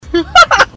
Laugh 12
Category: Comedians   Right: Both Personal and Commercial